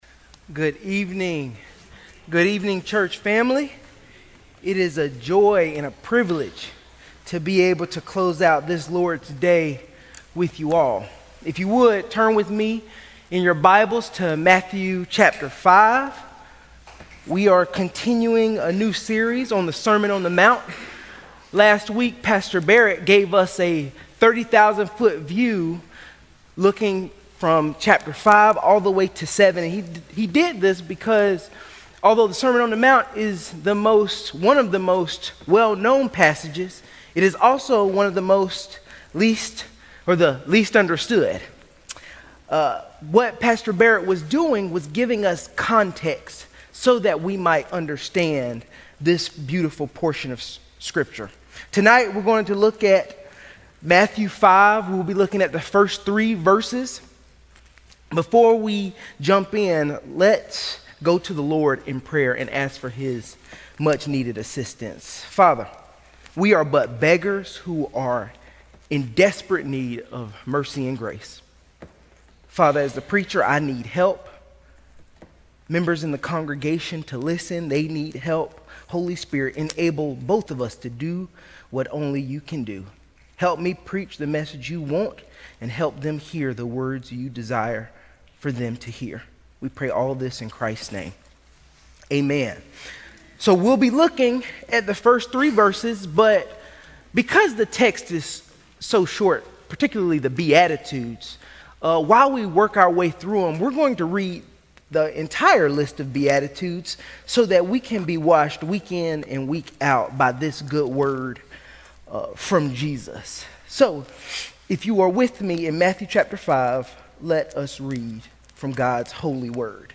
A message from the series "Sermon on the Mount."